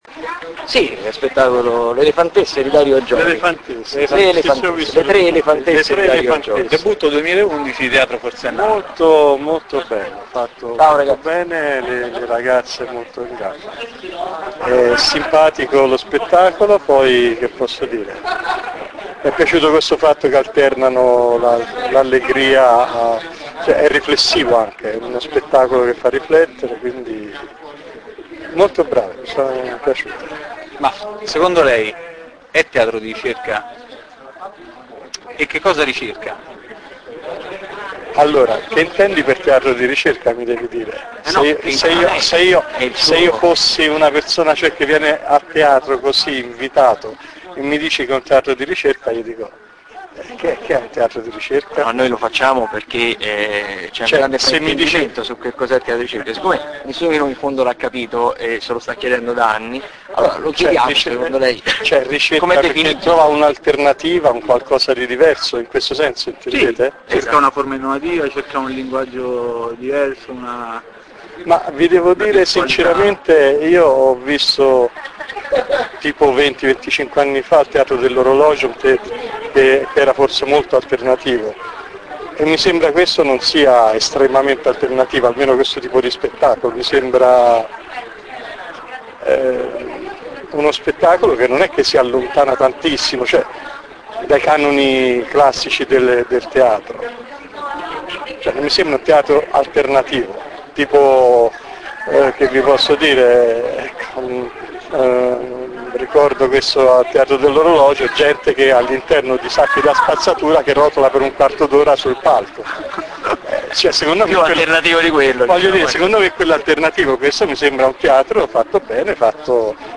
Ogni sera intervisteremo il pubblico porgendogli tre domande sugli spettacoli.
all’uscita de Le elefantesse di Teatro forsennato